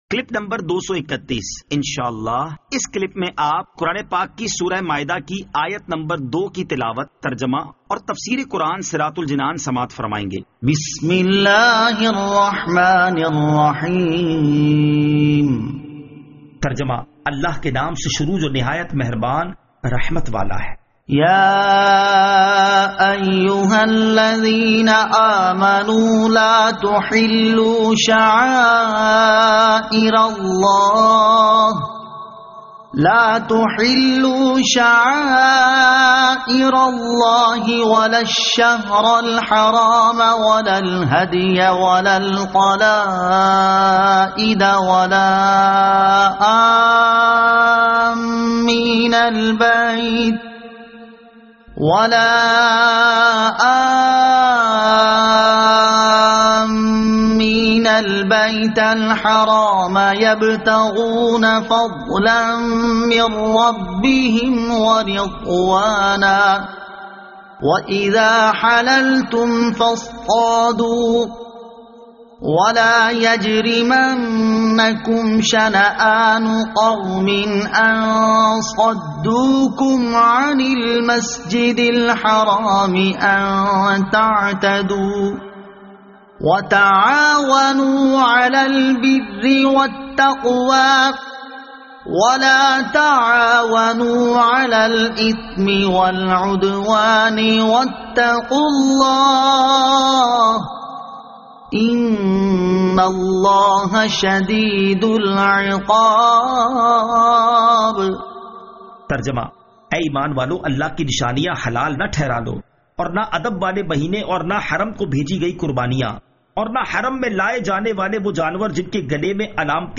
Surah Al-Maidah Ayat 02 To 02 Tilawat , Tarjama , Tafseer